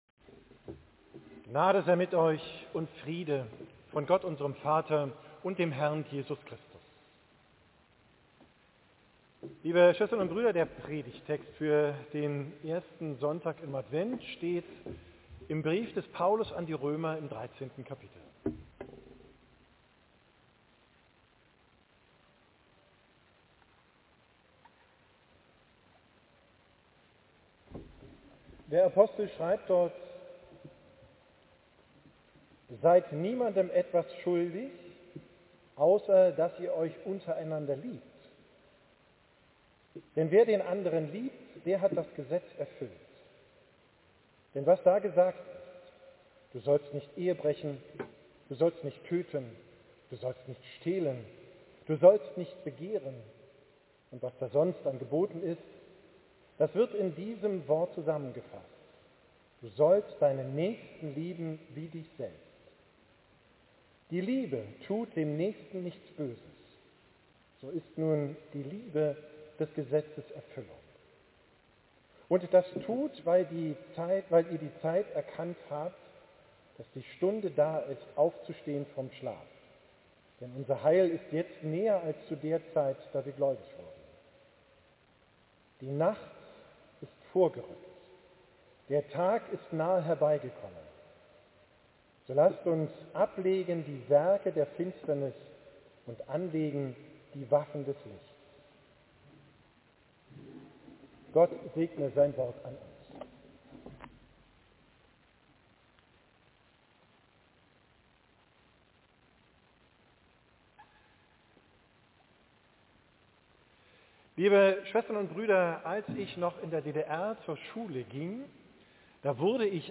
Predigt vom ersten Sonntag im Advent, 30.